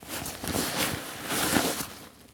foley_sports_bag_movements_09.wav